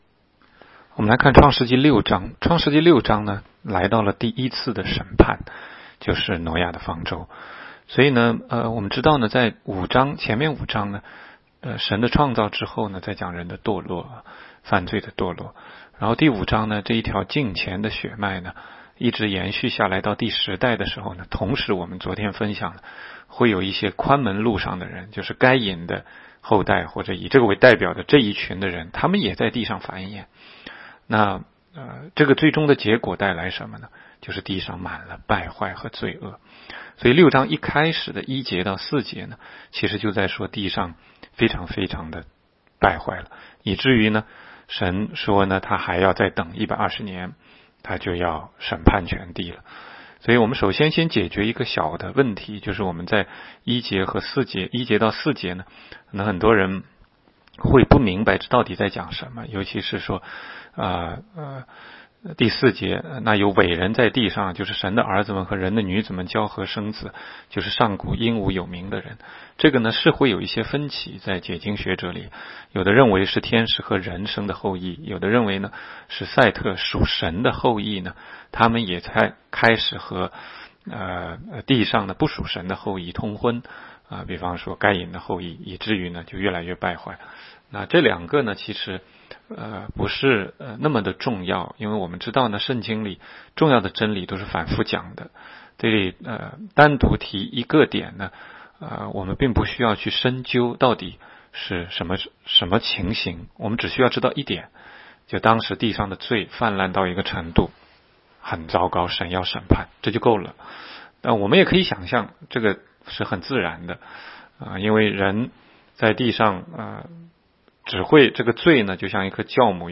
16街讲道录音 - 每日读经-《创世记》6章